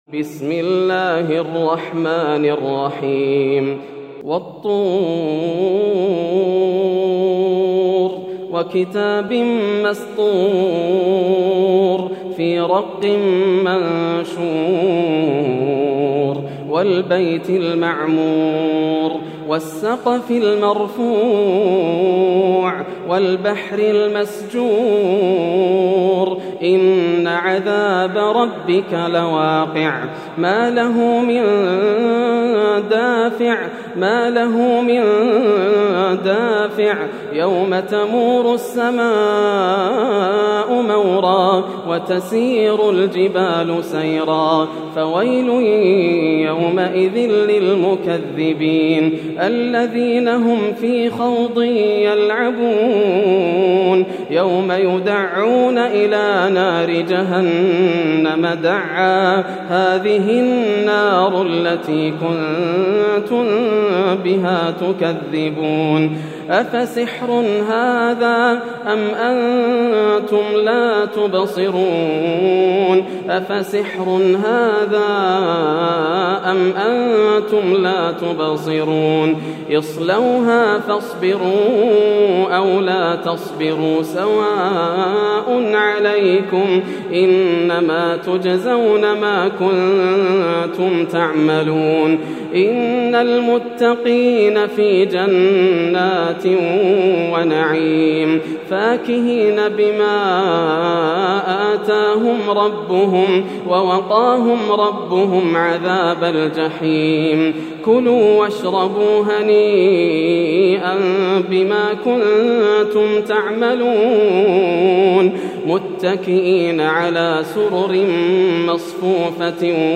سورة الطور > السور المكتملة > رمضان 1431هـ > التراويح - تلاوات ياسر الدوسري